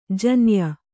♪ janya